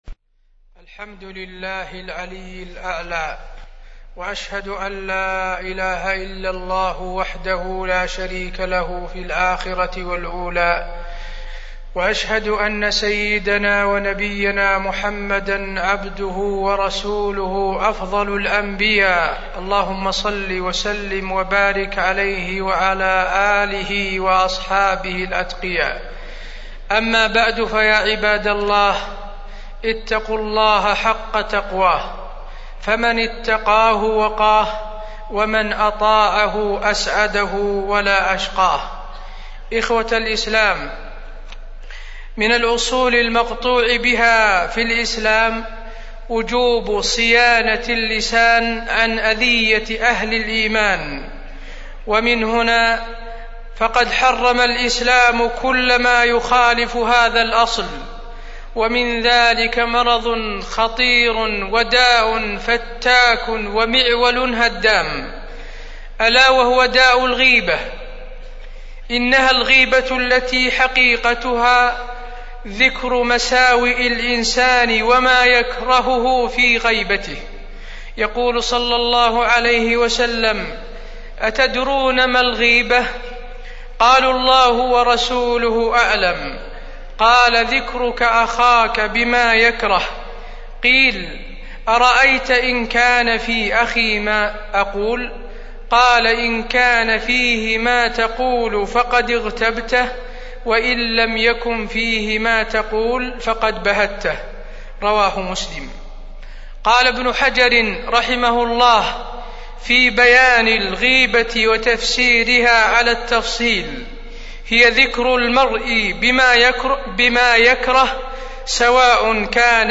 تاريخ النشر ١٦ شوال ١٤٢٦ هـ المكان: المسجد النبوي الشيخ: فضيلة الشيخ د. حسين بن عبدالعزيز آل الشيخ فضيلة الشيخ د. حسين بن عبدالعزيز آل الشيخ الغيبة والنميمة